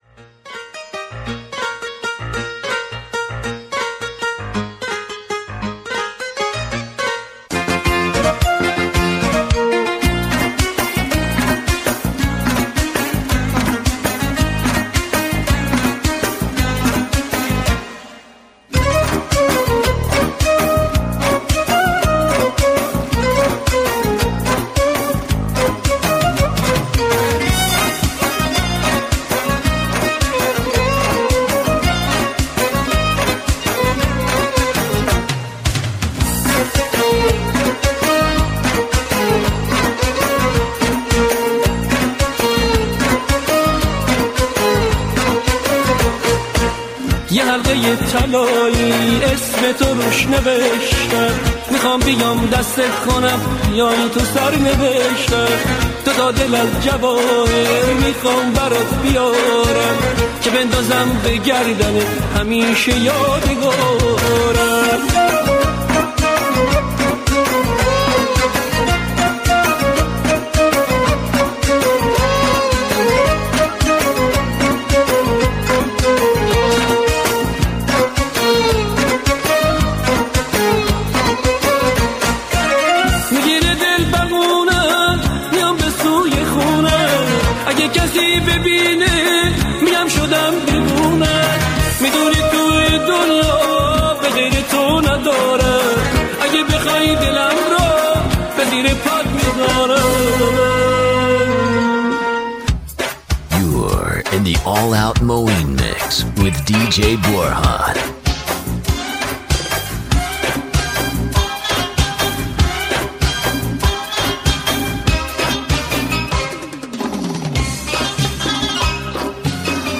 ژانر: پاپ
پادکست یک ساعته از شادترین موزیک های خاطره انگیز